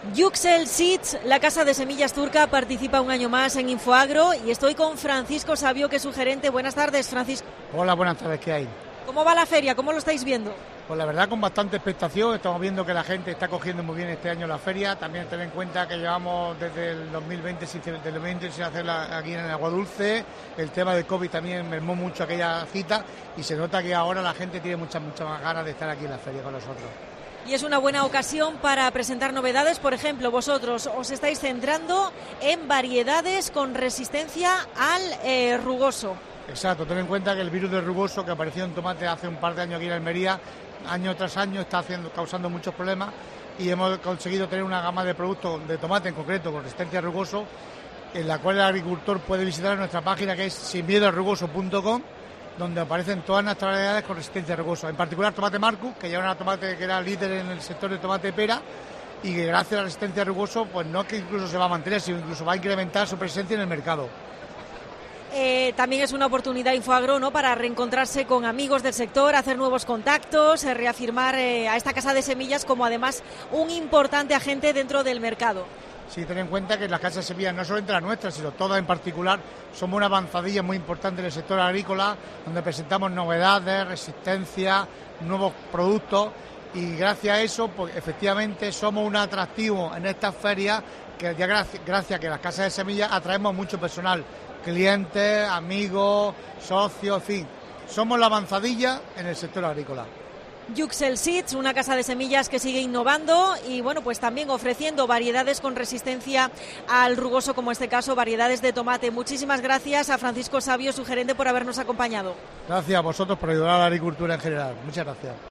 AUDIO: Entrevista en Infoagro